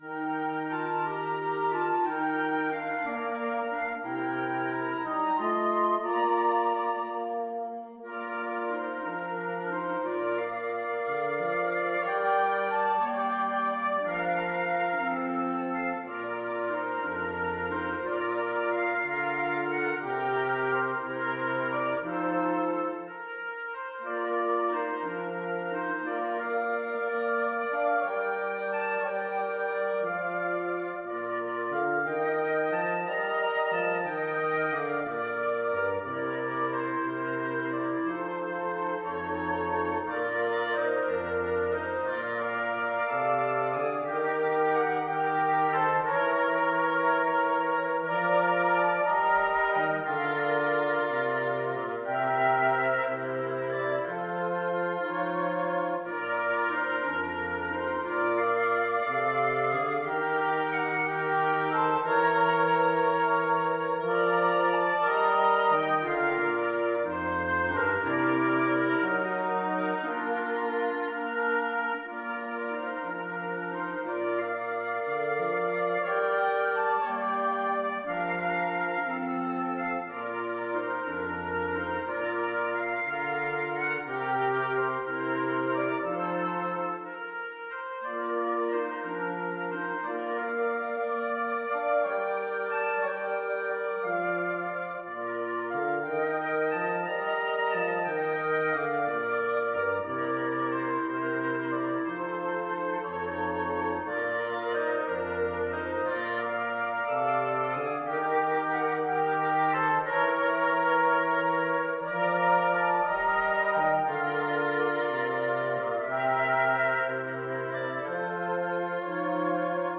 6/8 (View more 6/8 Music)
Gently and with expression . = c. 60
Wind Quartet  (View more Intermediate Wind Quartet Music)
Traditional (View more Traditional Wind Quartet Music)